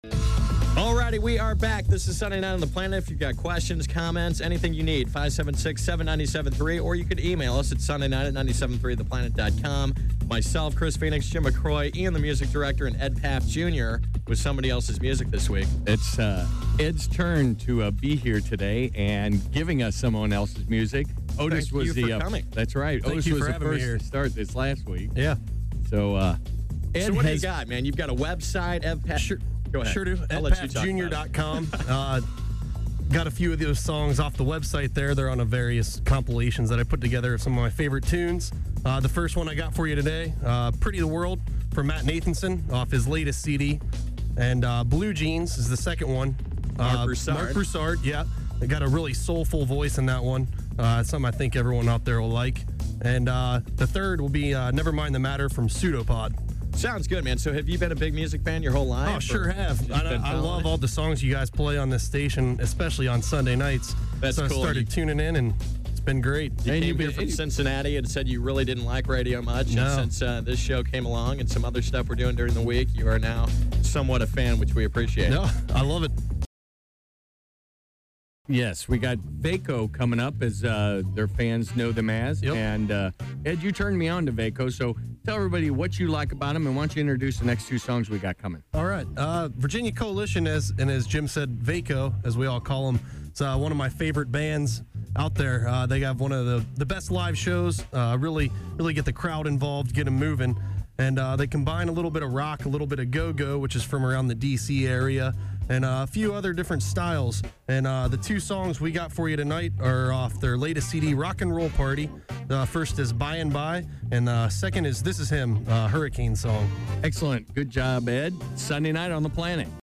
Here I am actually live on the radio! There are two segments that were taken from two different parts of the show with a couple seconds on silence between the two.